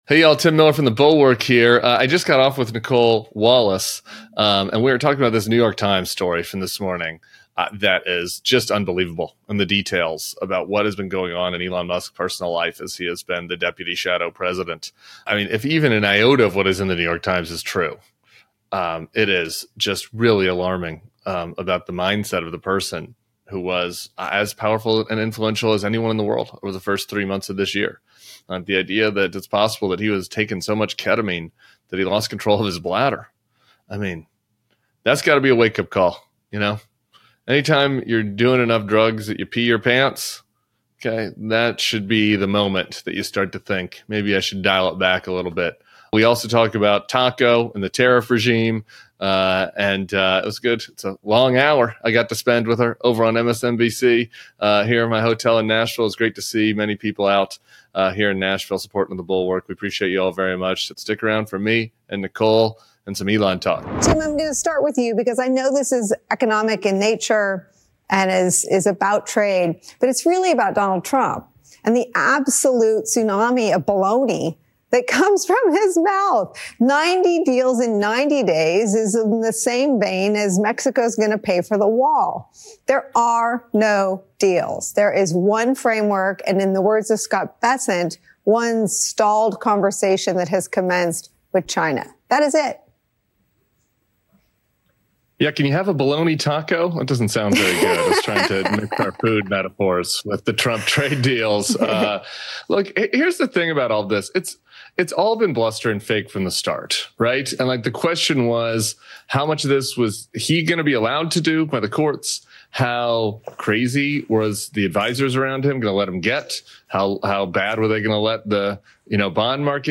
Tim Miller joins Nicolle Wallace on MSNBC’s Deadline: White House to react to the bombshell New York Times report on Elon Musk’s alleged drug use, erratic behavior, and alarming influence over U.S. policy. They also discuss Trump’s dangerous push to dismantle legal checks on executive power and how his tariff chaos is affecting small businesses.